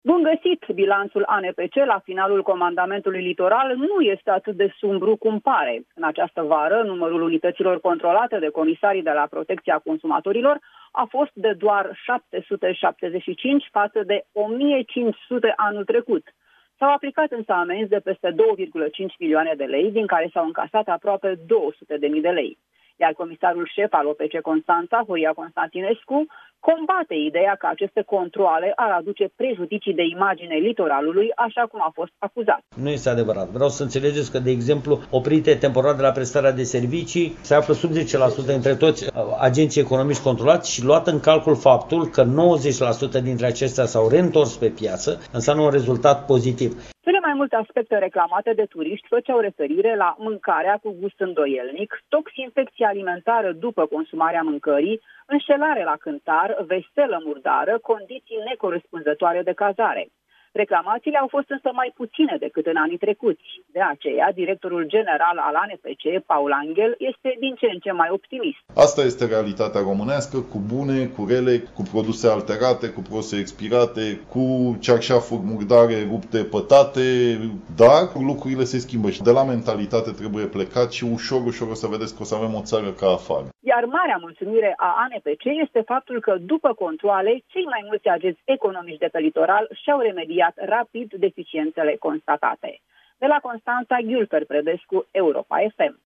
O corespondență